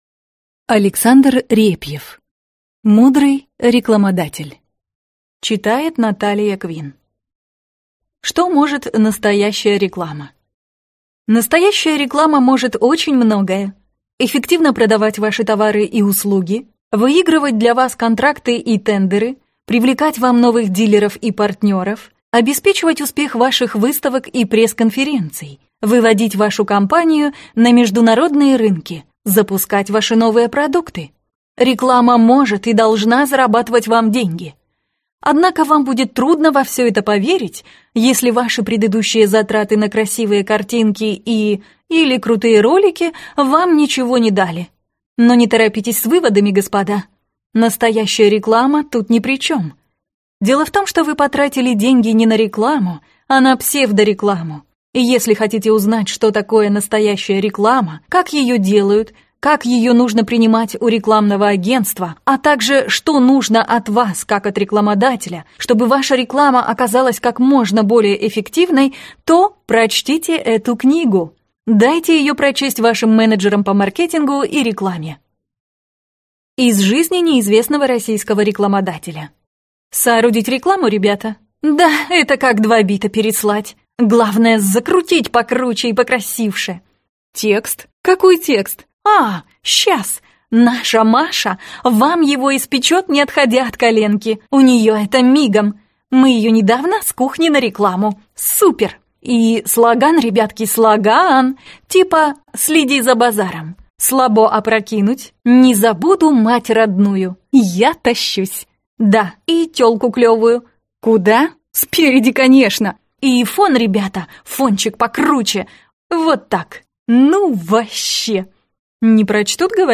Аудиокнига Мудрый рекламодатель | Библиотека аудиокниг